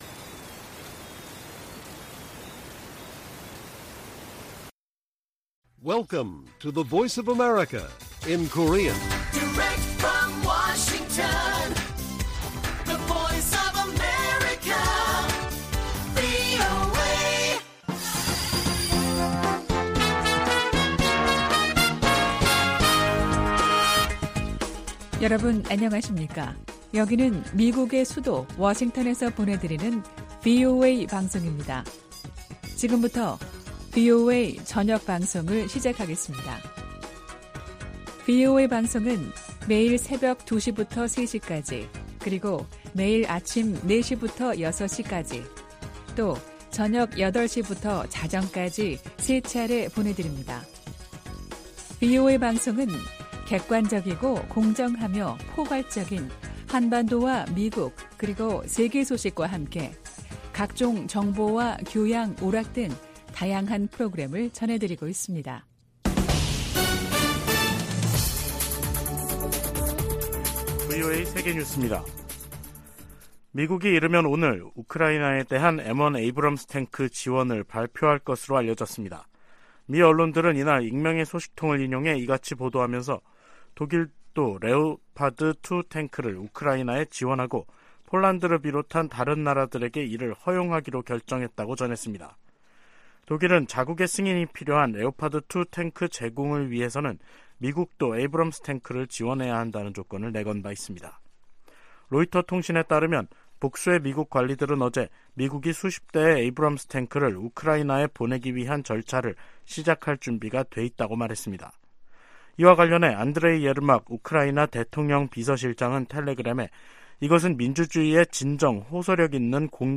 VOA 한국어 간판 뉴스 프로그램 '뉴스 투데이', 2023년 1월 25일 1부 방송입니다. 미 국무부는 줄리 터너 북한인권특사 지명자에 대한 조속한 상원 인준을 촉구했습니다. 미국의 인권 전문가들은 줄리 터너 지명자가 북한인권을 오랫동안 다룬 경험과 전문성을 갖춘 적임자라고평가했습니다.